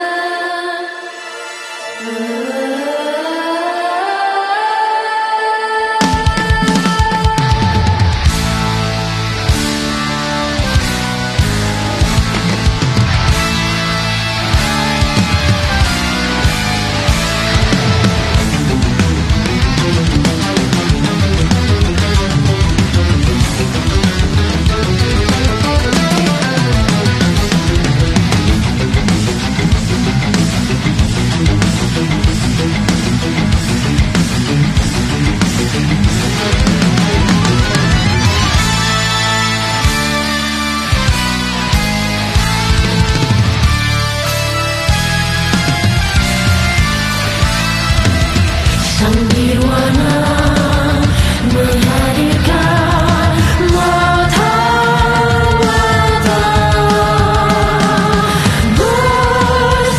Adding modulation and delay from sound effects free download
Adding modulation and delay from a single hardware device!